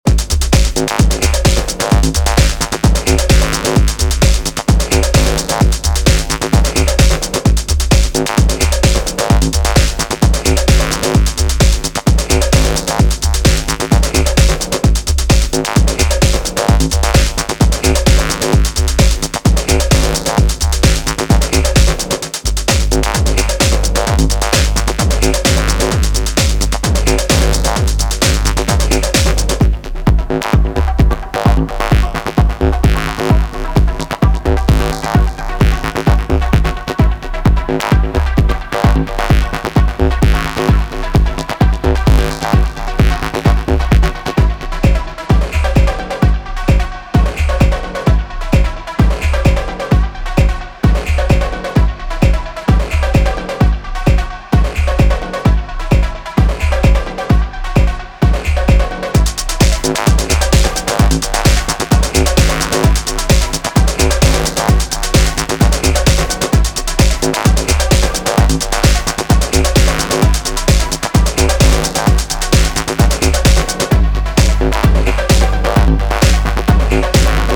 ビキビキと放電するかの如き激しい刺激のシーケンスを特徴とする4トラックス。
荒れたシンセとミニマルリフがトランスを誘発する